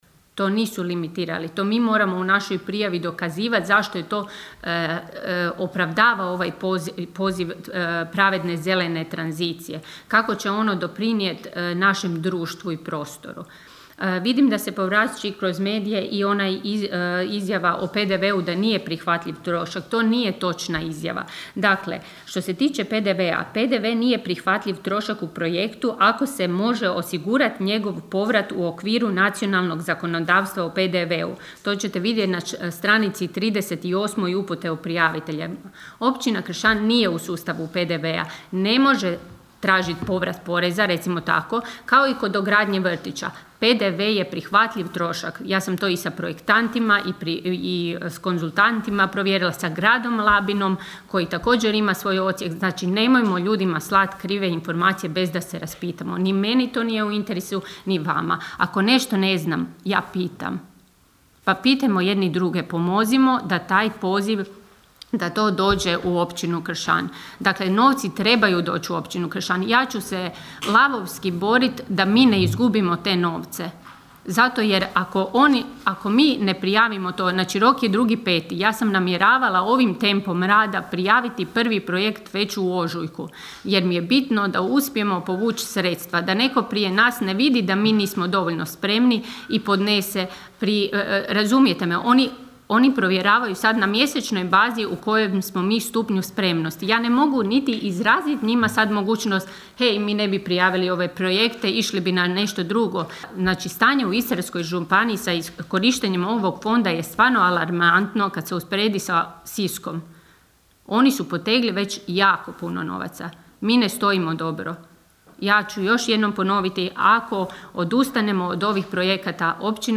Sjednica Općina Kršan
Kazala je ona kako je minimalno sufinanciranje projekata 85%, a maksimalno 100%: (